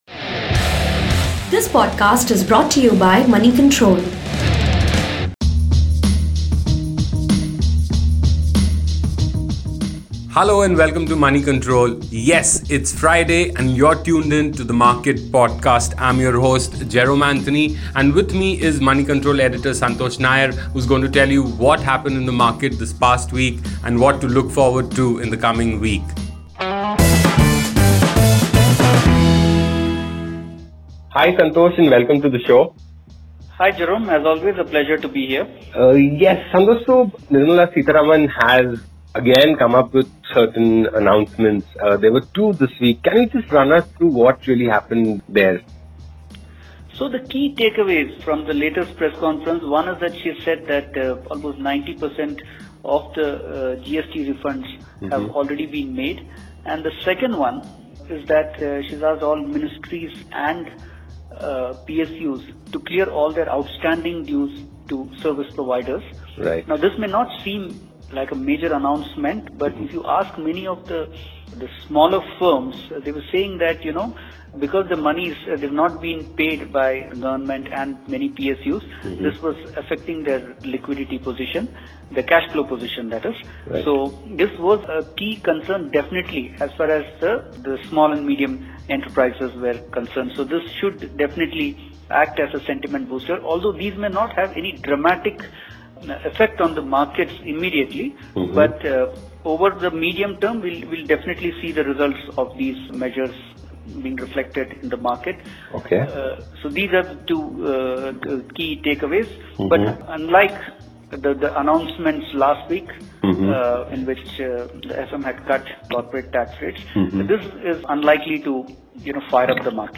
gets in conversation